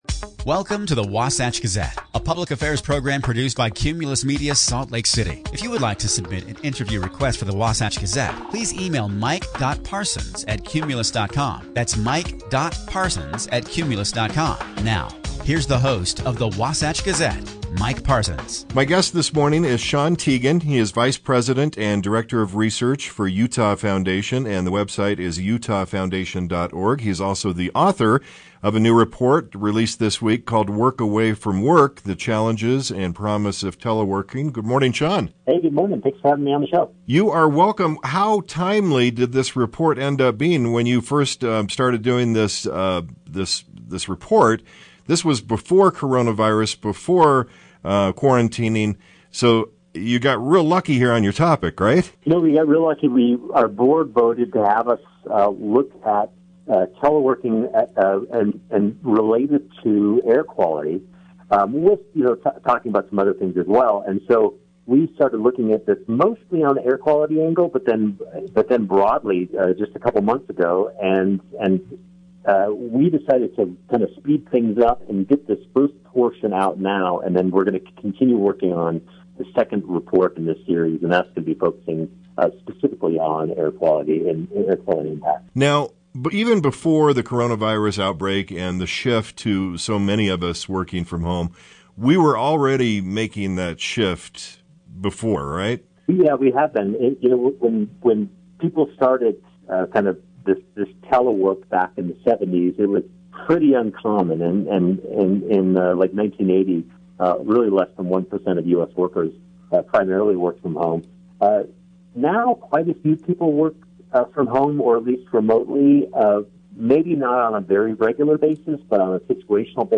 Listen to an mp3 of the conversation here.